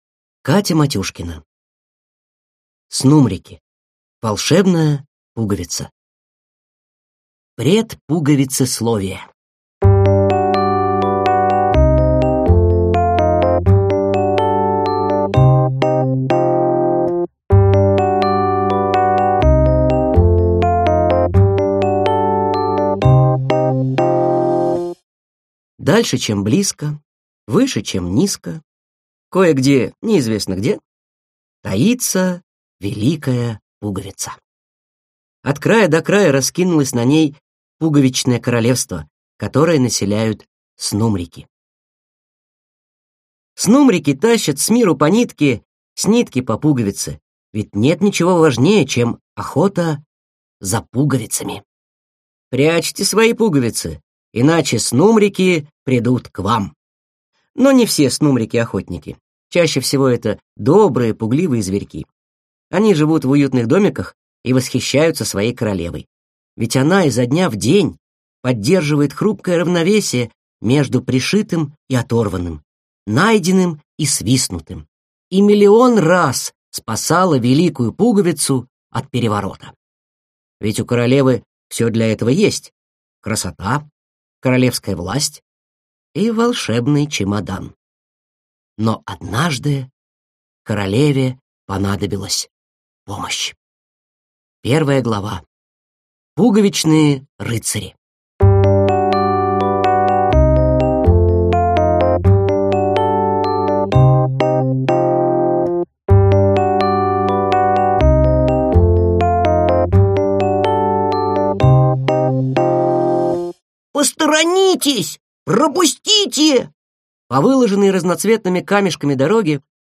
Аудиокнига Снумрики. Волшебная пуговица | Библиотека аудиокниг